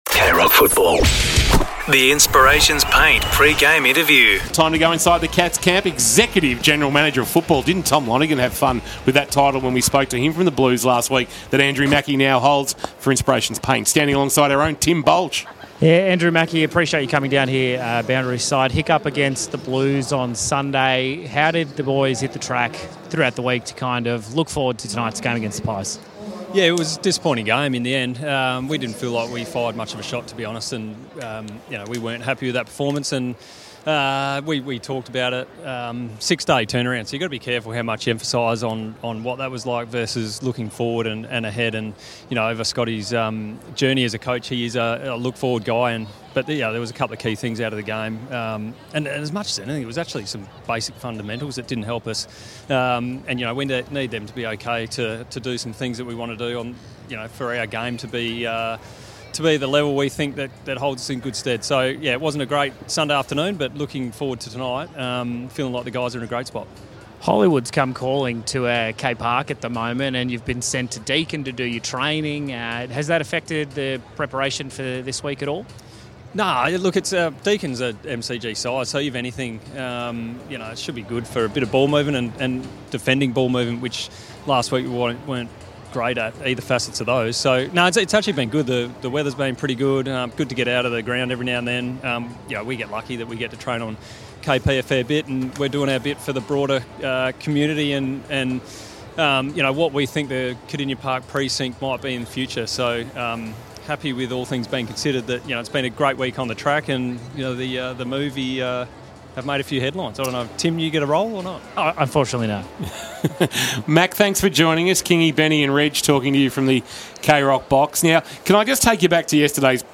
2025 - AFL - Round 8 - Collingwood vs. Geelong: Pre-match interview